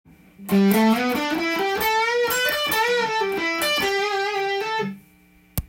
③のフレーズはGメジャーペンタトニックスケールを使用していますが
最後の音程差に渋さが爆発しています。伸ばして溜めて　の流れもカッコいいですね！